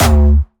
Jumpstyle Kick 4